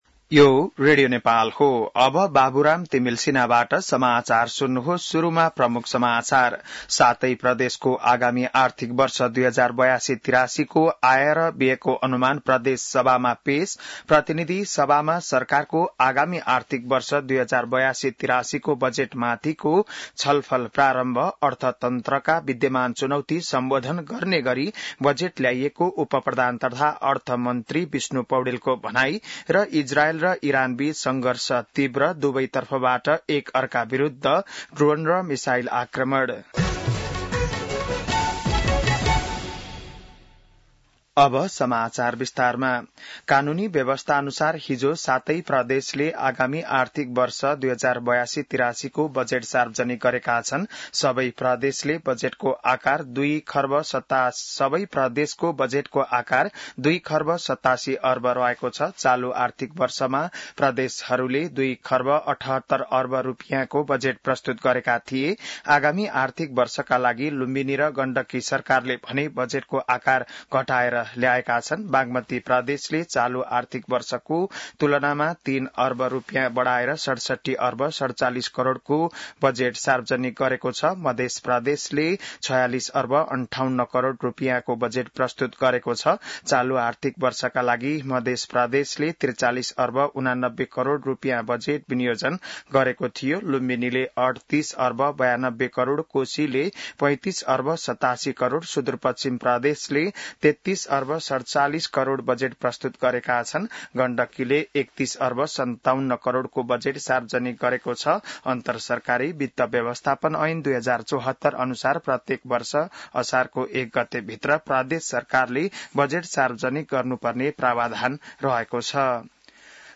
बिहान ९ बजेको नेपाली समाचार : २ असार , २०८२